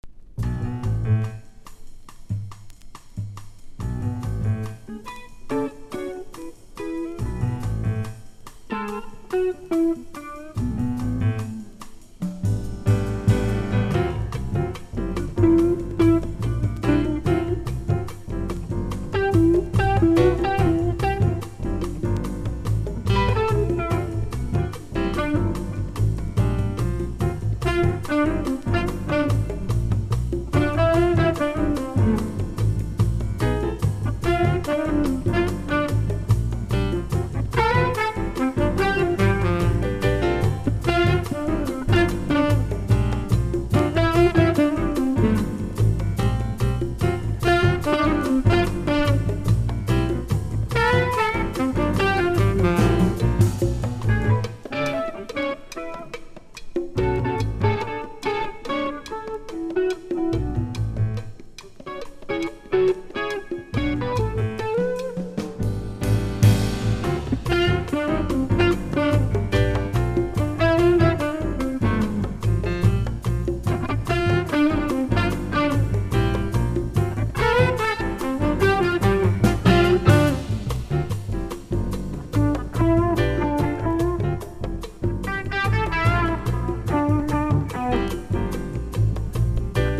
JAZZ FUNK / SOUL JAZZ